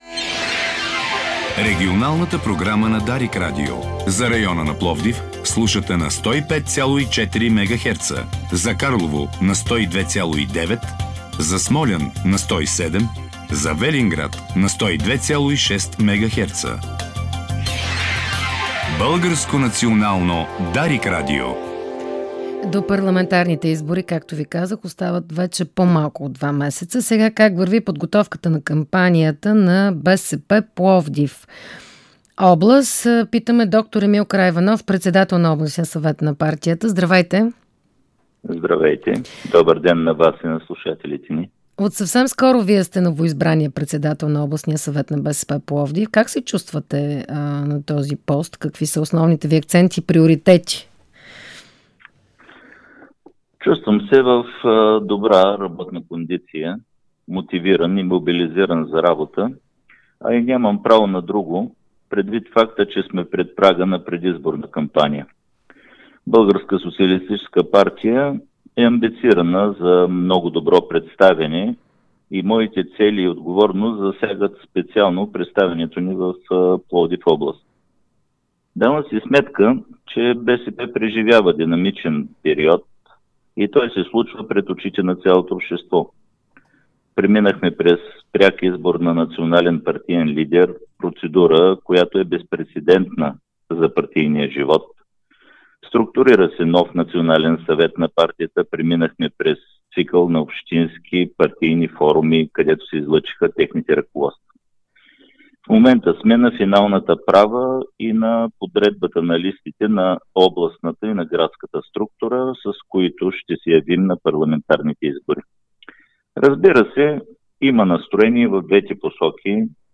Това заяви в ефира на Дарик радио Пловдив новоизбраният председател на БСП Пловдив област д-р Емил Караиванов, който е и бивш кмет два мандата на Асеновград.